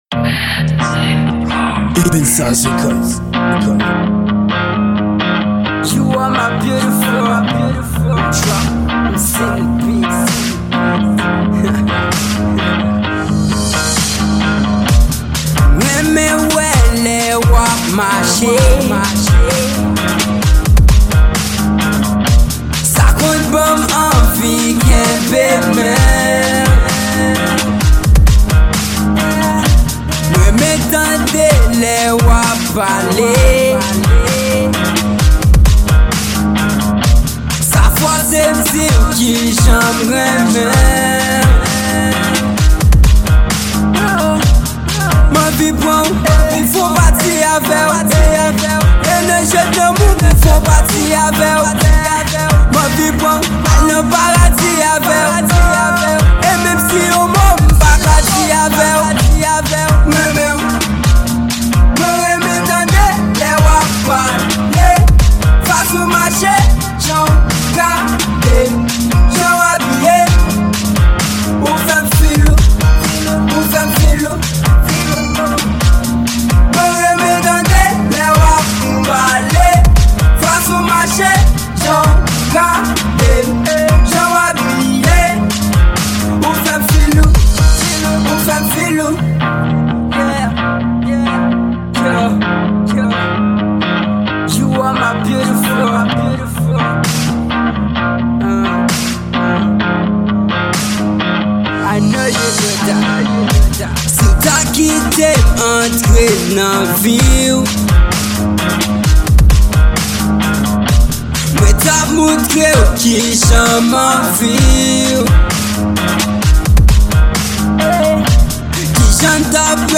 Genre: R&B.